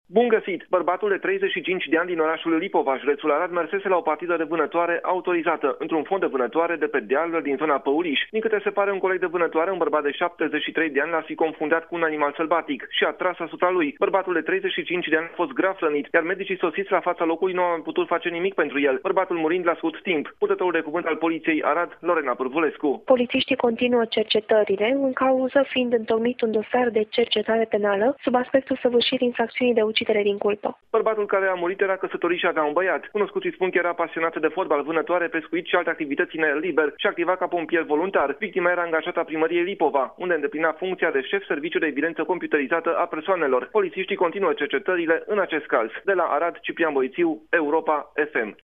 Corespondentul Europa FM